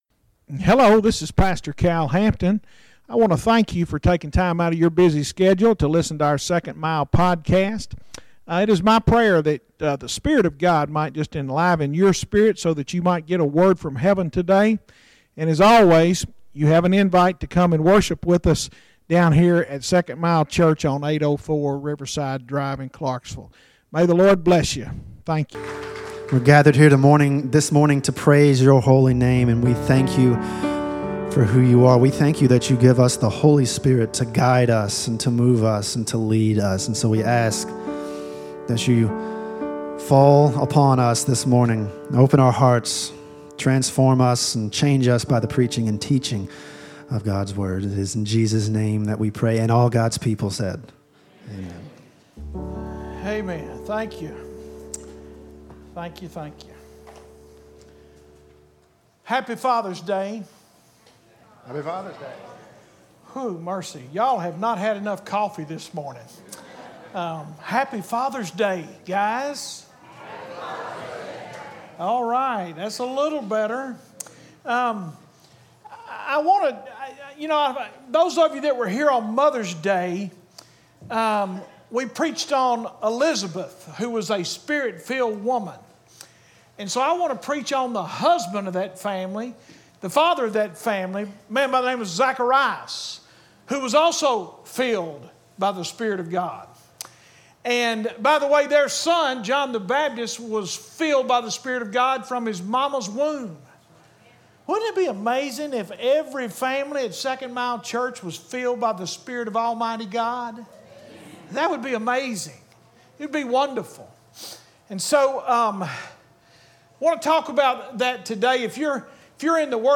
Bible Study Isaiah Ch 36 & 37